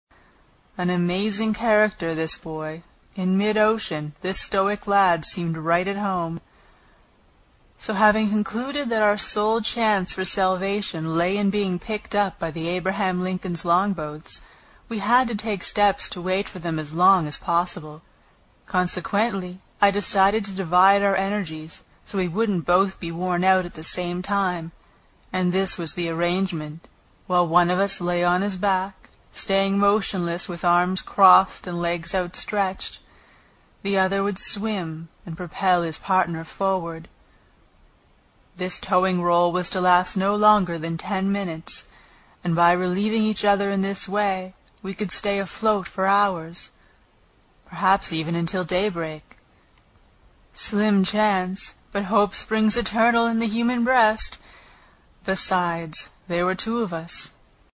英语听书《海底两万里》第82期 第7章 一种从未见过的鱼(5) 听力文件下载—在线英语听力室
在线英语听力室英语听书《海底两万里》第82期 第7章 一种从未见过的鱼(5)的听力文件下载,《海底两万里》中英双语有声读物附MP3下载